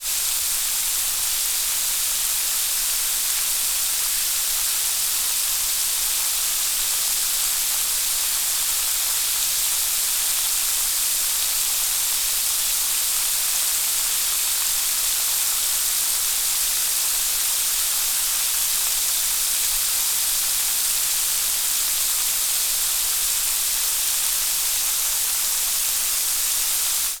Water, Spray, Leak From Connection, Constant SND35349 2.wav